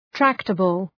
Προφορά
{‘træktəbəl}